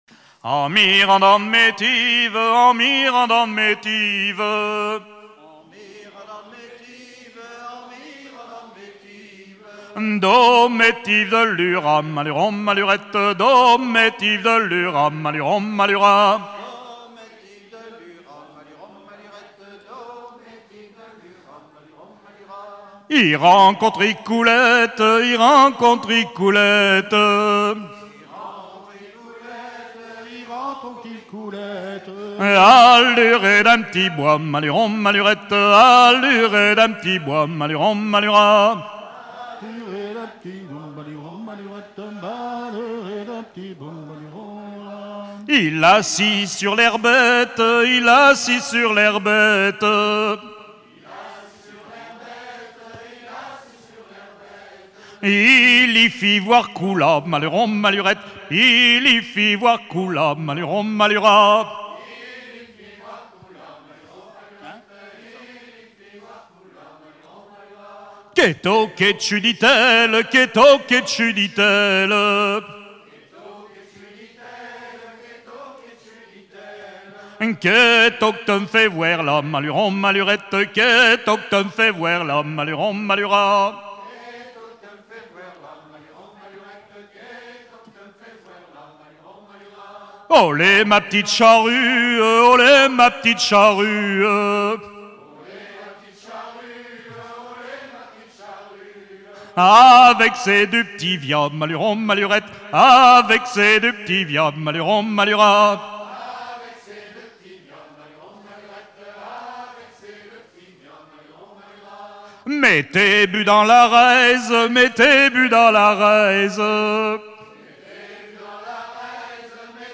Genre laisse
Festival de la chanson traditionnelle - chanteurs des cantons de Vendée
Pièce musicale inédite